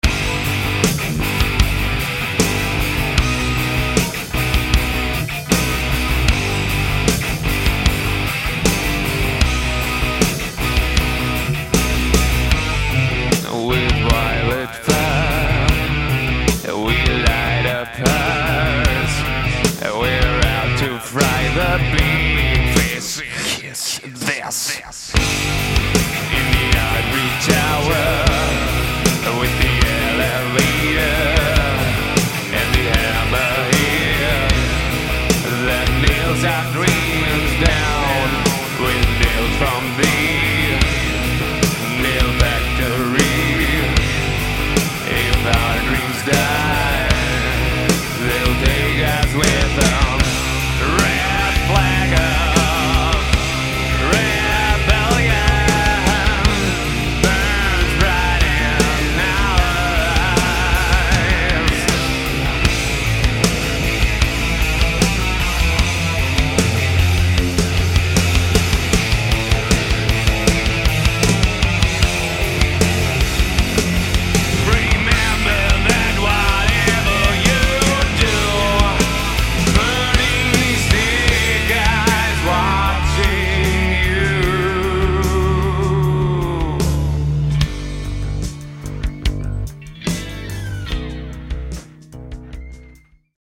modern, meaningful rock with a darkish twist